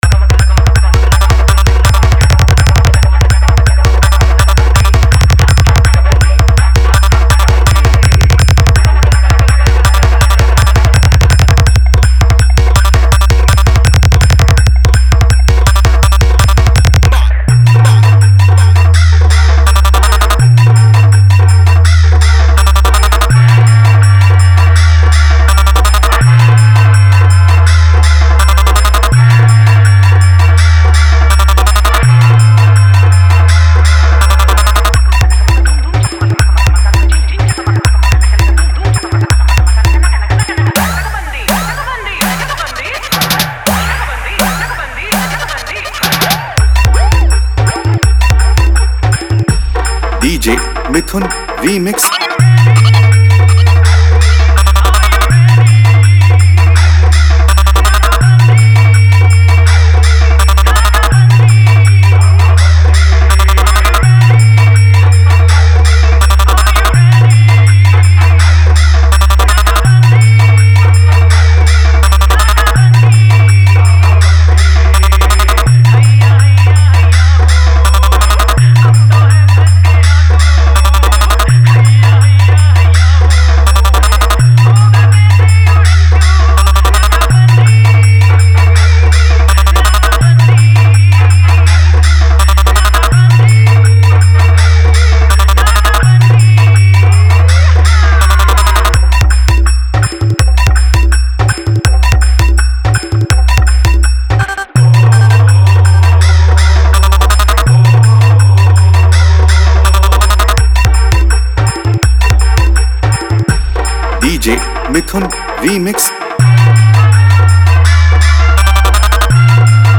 Hindi New Style Dance Dhamaka Mix